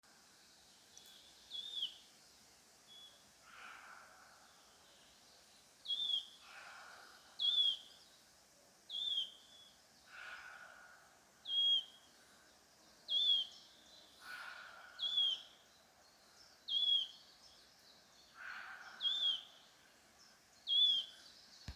Svirlītis, Phylloscopus sibilatrix
Administratīvā teritorijaLīvānu novads
StatussUztraukuma uzvedība vai saucieni (U)